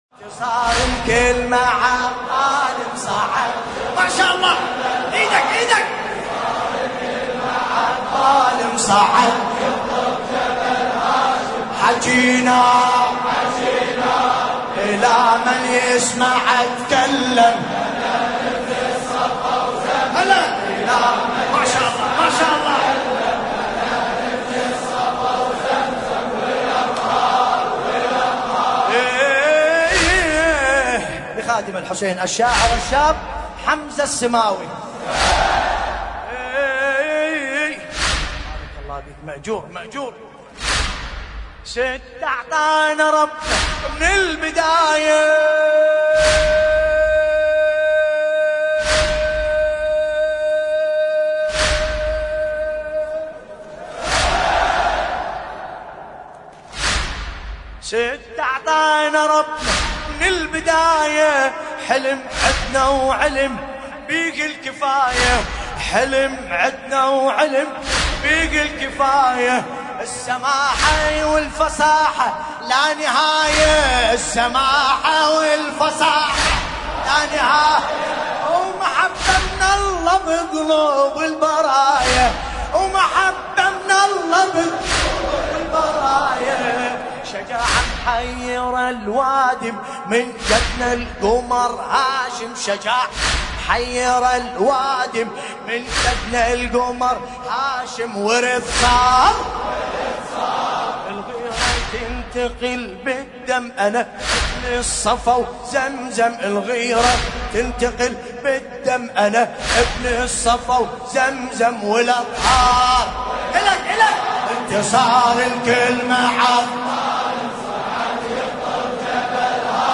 ملف صوتی انتصار الكلمة عالظالم بصوت باسم الكربلائي